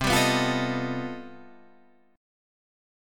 C Minor Major 13th